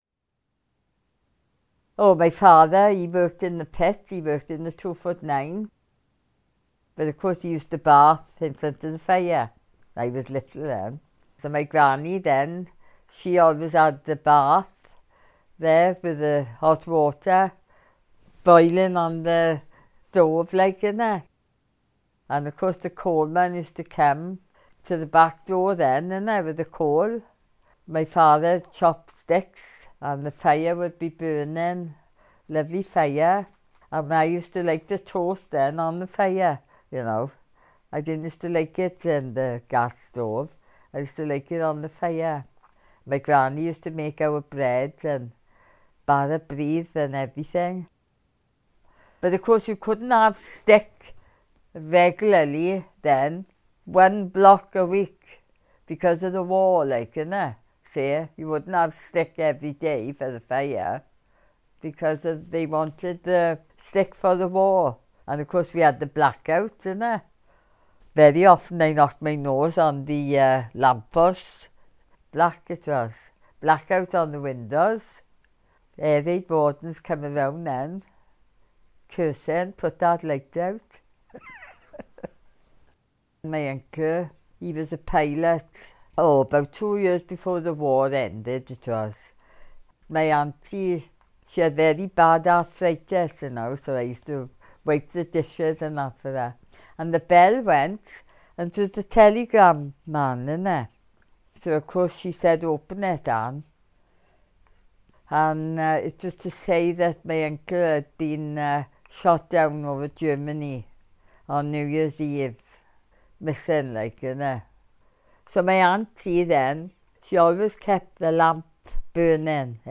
Edited oral history interview Ynysybwl 2015